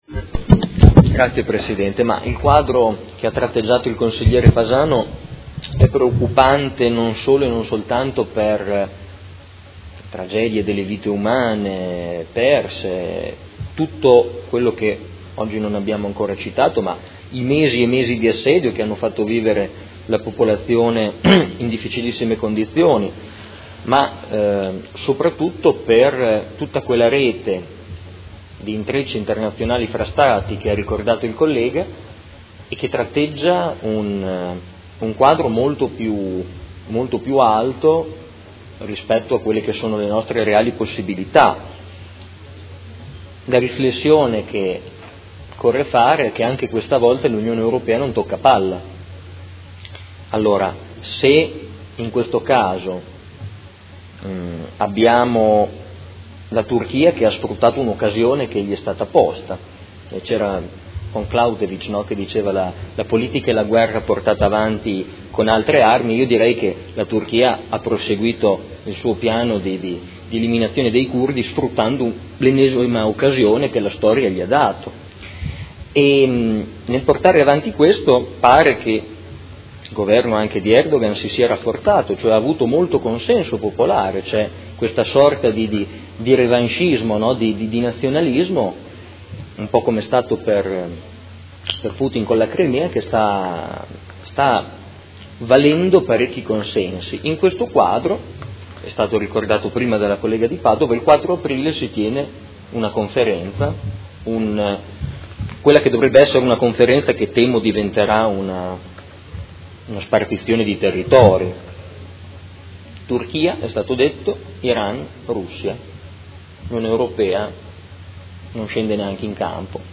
Seduta del 26 marzo 2018